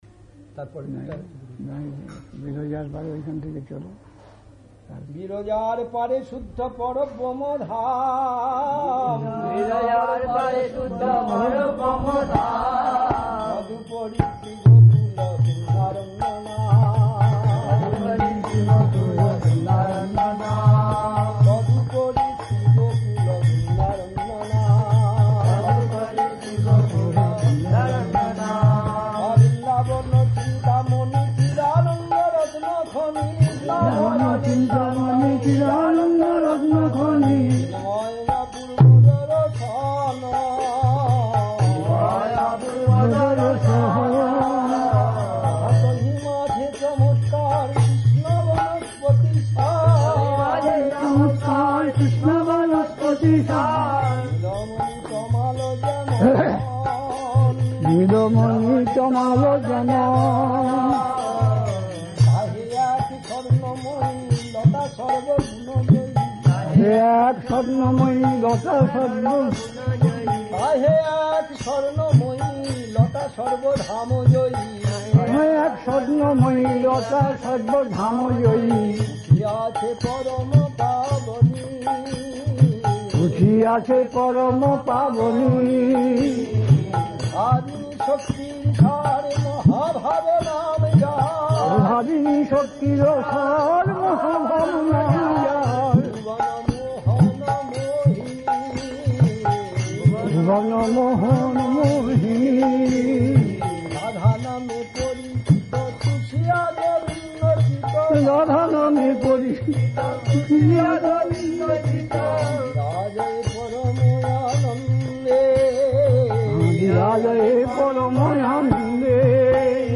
It's being posted as vintage classic recordings.